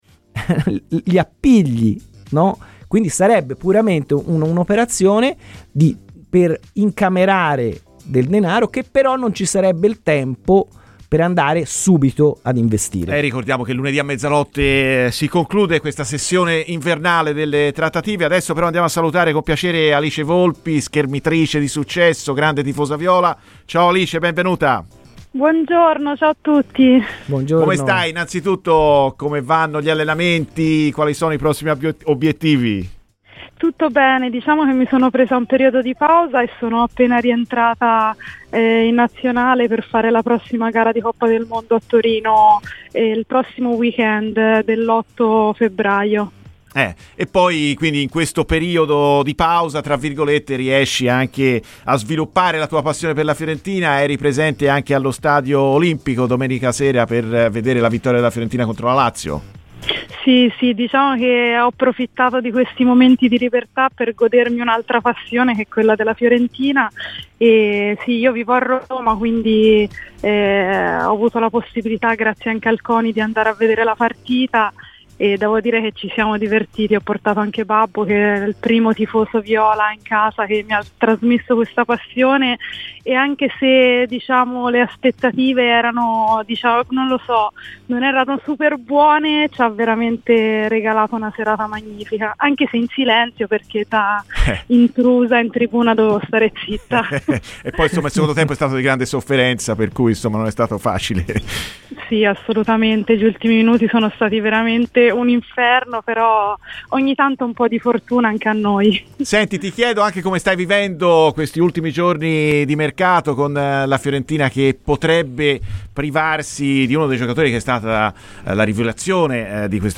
La schermitrice pluripremiata Alice Volpi, grande tifosa viola ha parlato così ai microfoni di Radio FirenzeViola, durante "Viola Amore Mio": "I meccanismi del calcio sono un po' questi: se si riesce a vendere un giocatore è sempre un'opportunità, ma un giocatore che ha solo 19 anni che è cresciuto nella Primavera e non si gode nemmeno quello che ha costruito in tutti gli anni".